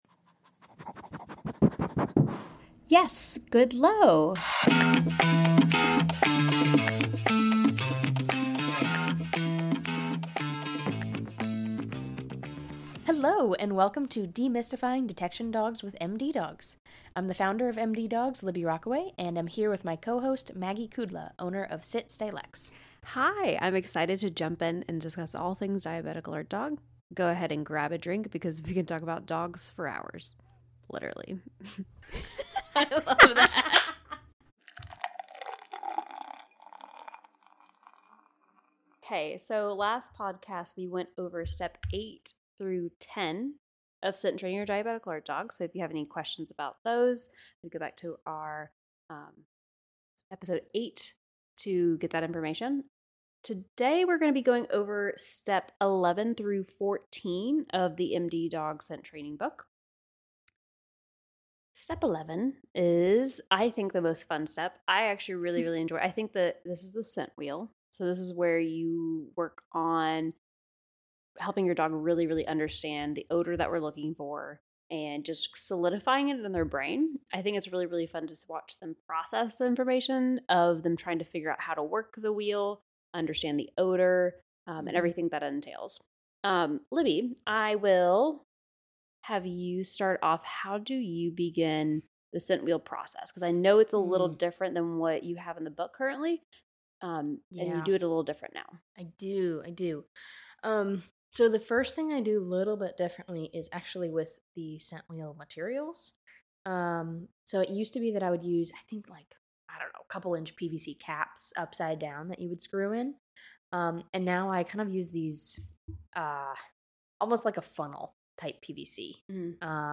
Grab a drink, and enjoy two awkward dog trainers attempt to make a podcast.